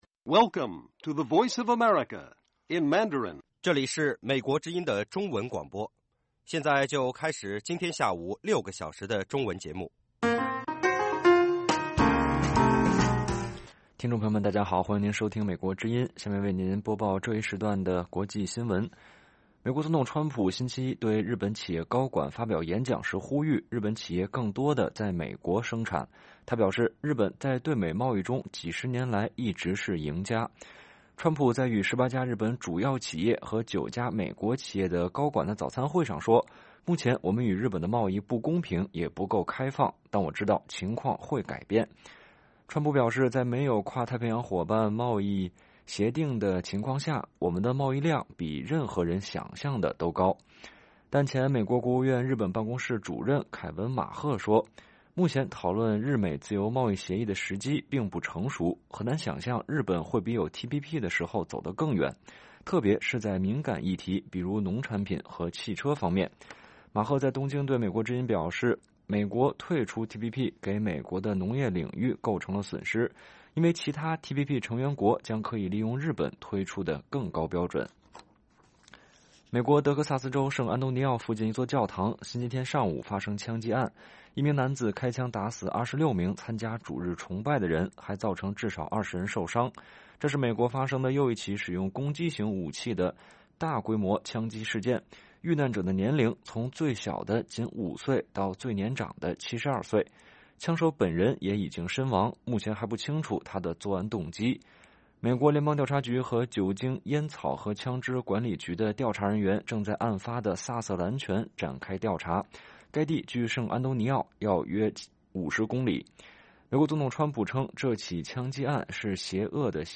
北京时间下午5-6点广播节目。广播内容包括国际新闻，新动态英语，以及《时事大家谈》(重播)